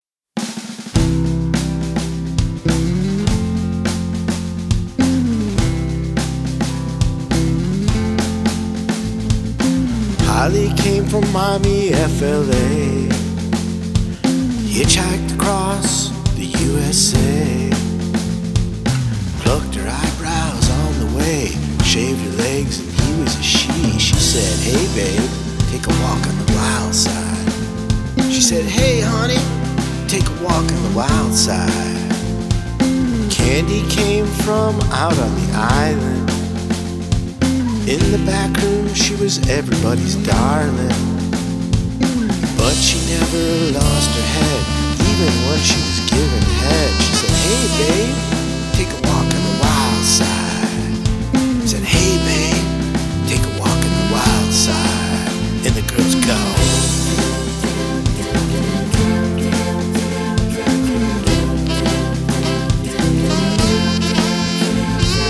cover album